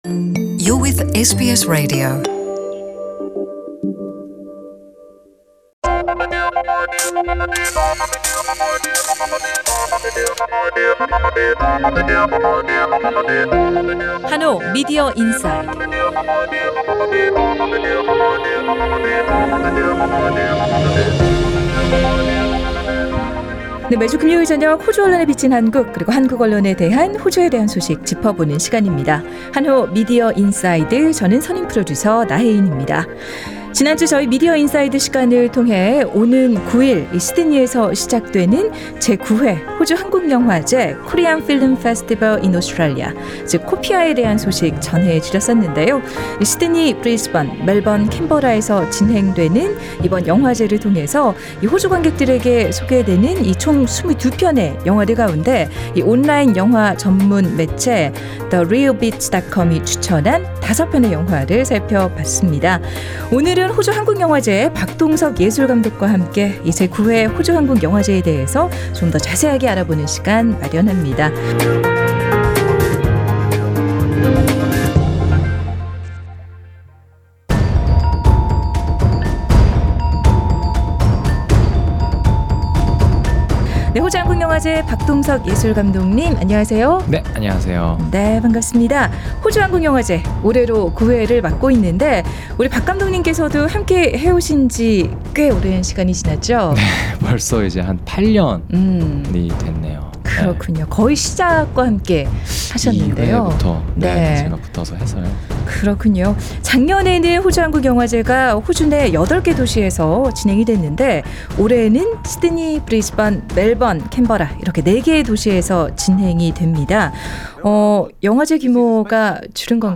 [상단의 팟캐스트를 통해 전체 인터뷰를 들으실 수 있습니다.]